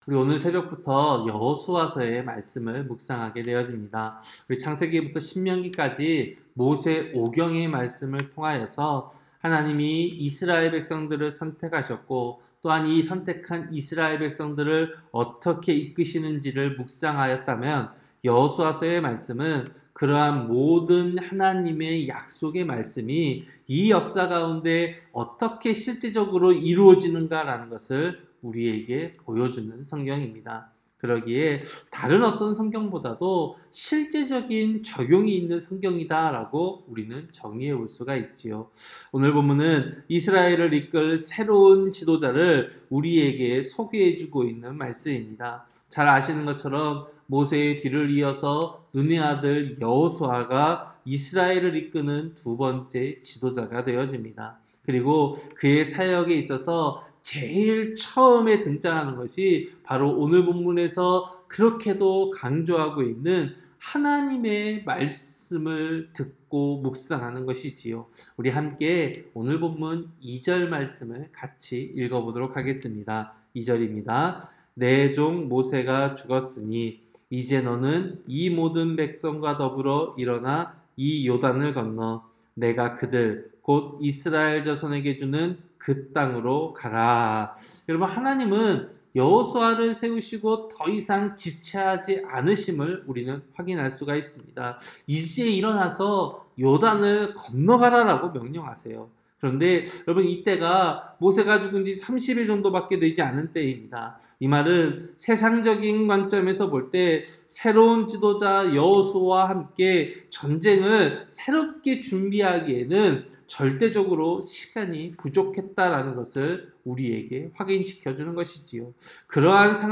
새벽설교-여호수아 1장